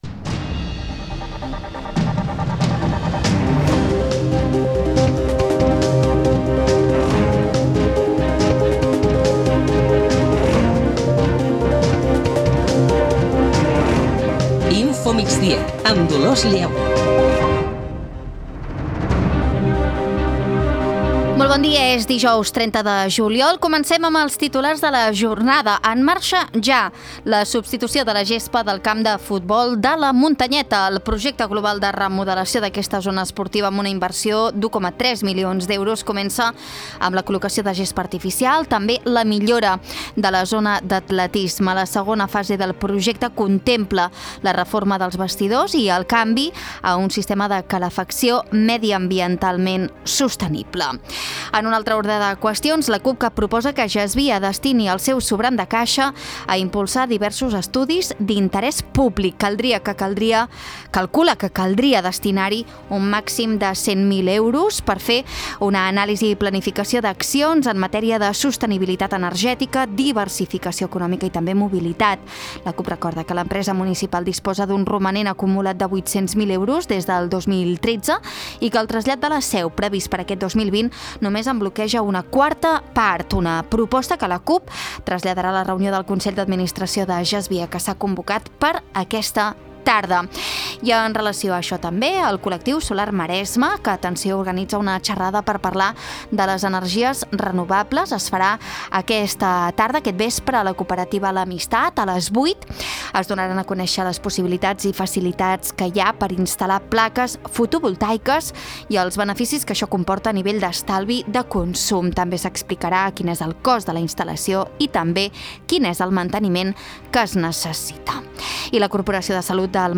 Notícies locals i comarcals.